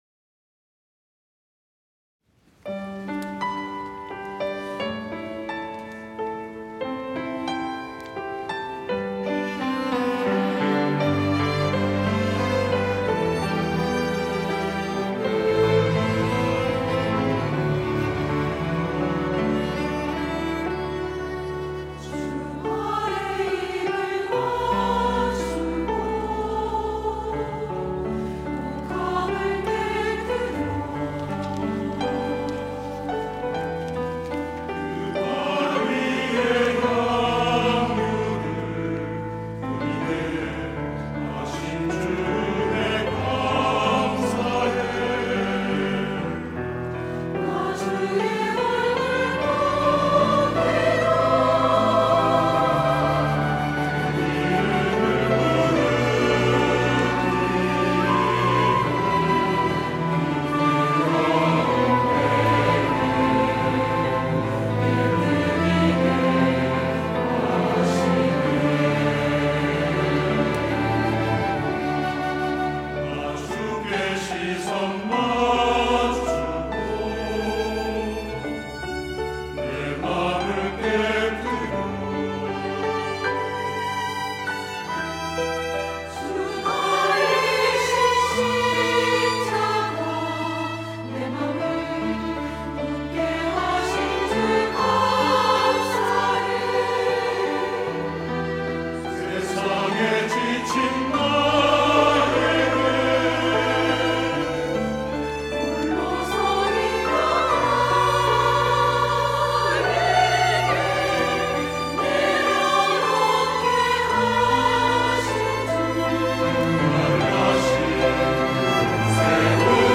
호산나(주일3부) - 옥합
찬양대